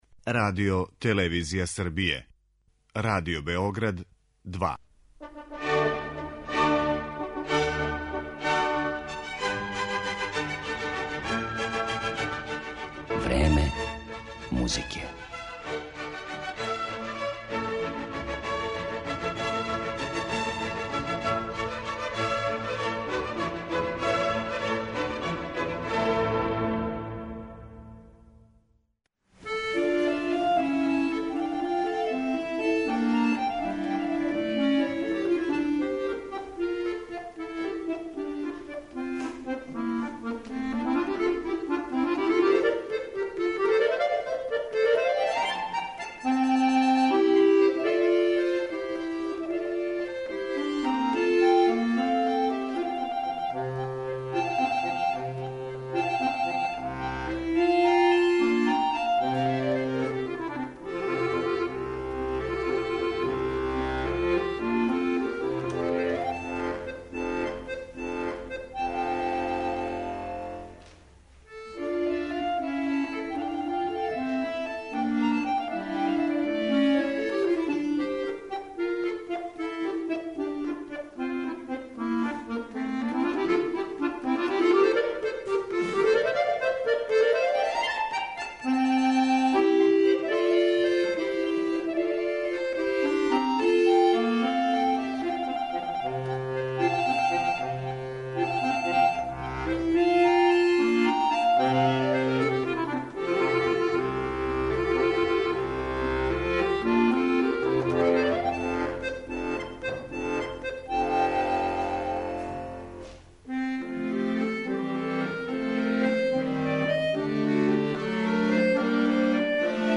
Александер Севастијан - виртуоз на хармоници